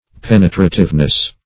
Penetrativeness \Pen"e*tra*tive*ness\, n.
penetrativeness.mp3